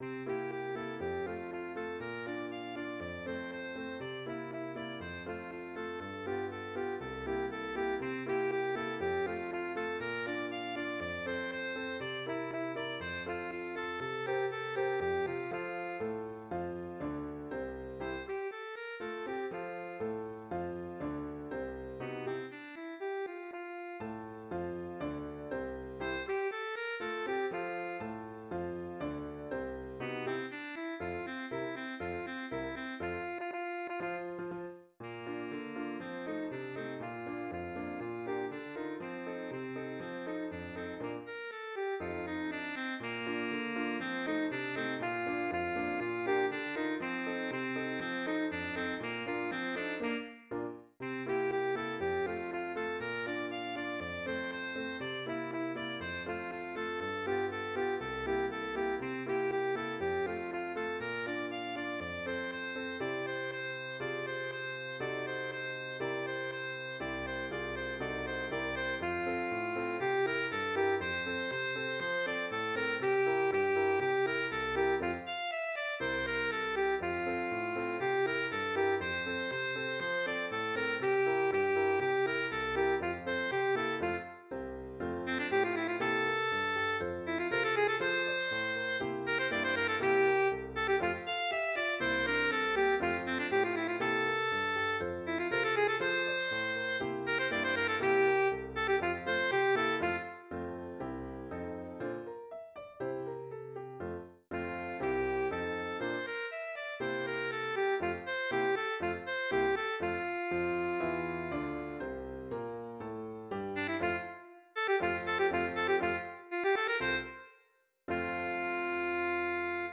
Voicing: Alto Saxophone and Piano